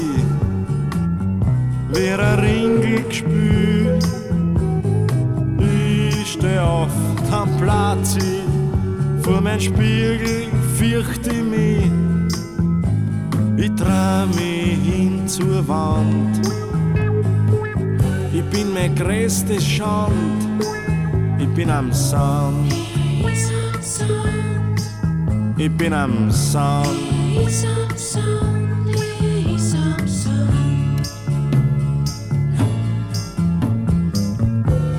Жанр: Рок
Prog-Rock, Art Rock, Rock